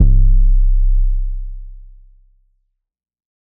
SOUTHSIDE_808_bidness_C.wav